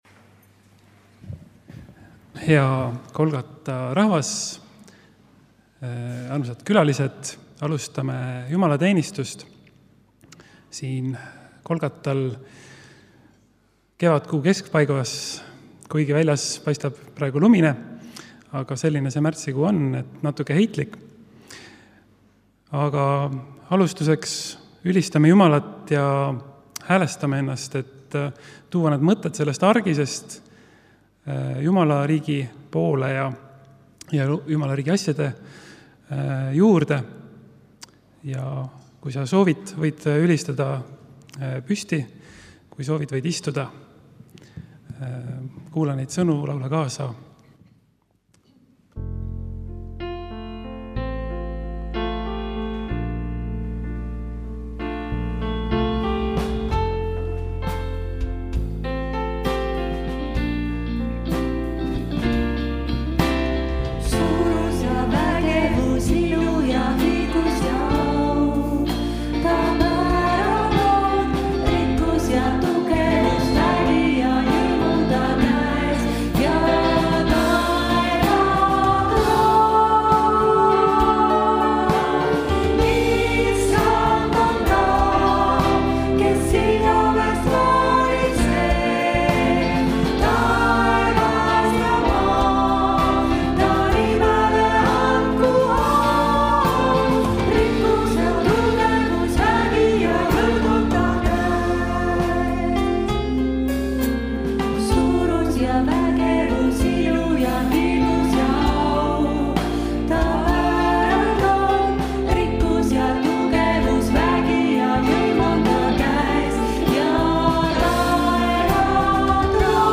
Kõik jutlused
Pühakirja lugemine: Jk 2:14-18